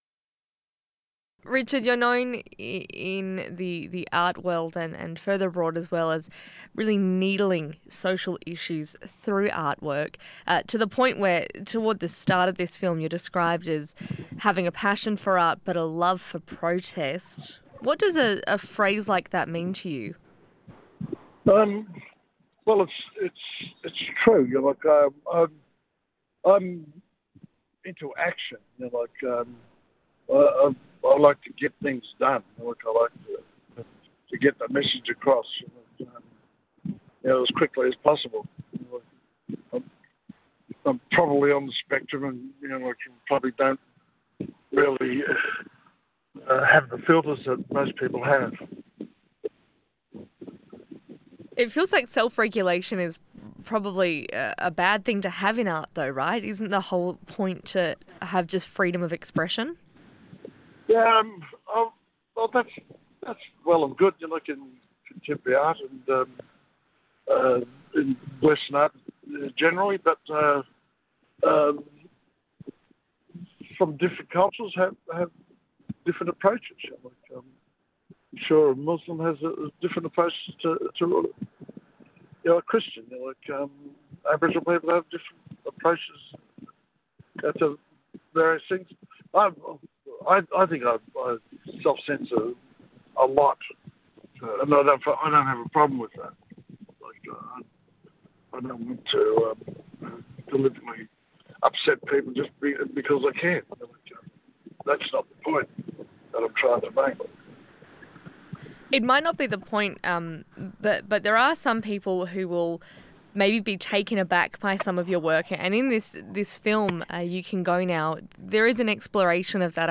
He phoned into Breakfast